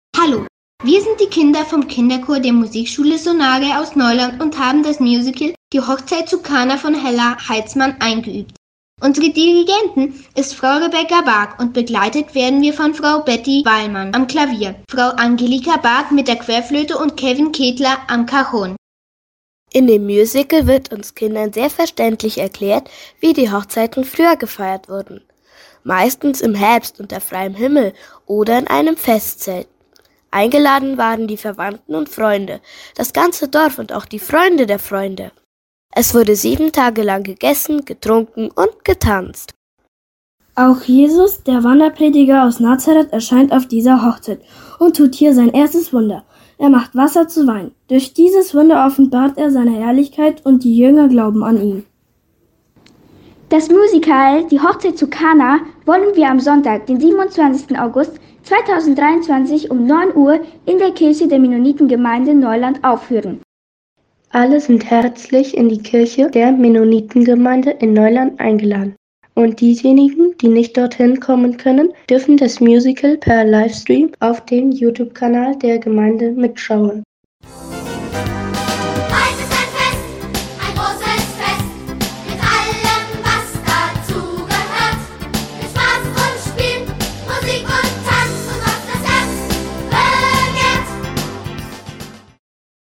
2023-08-25_Kindermusical Hochzeit zu Kana.